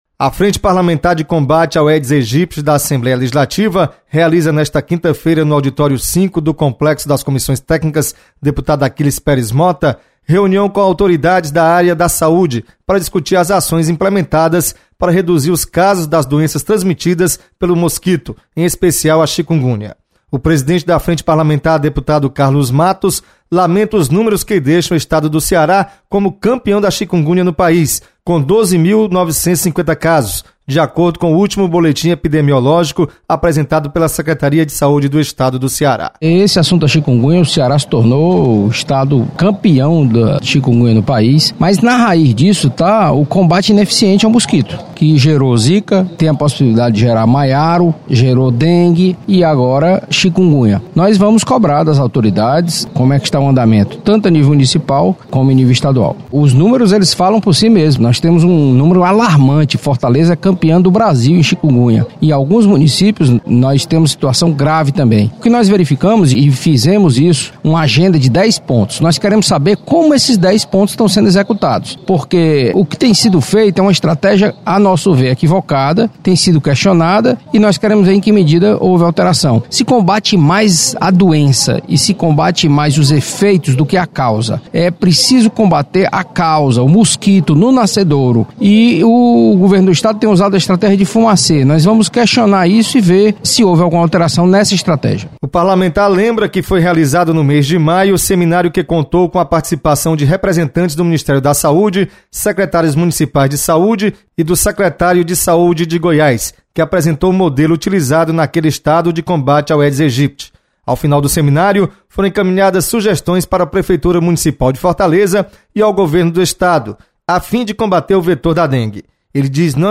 Frente Parlamentar de Combate ao Aedes aeqgypti realiza reunião para avaliar combate à Dengue, Zica e Chikungunya. Repórter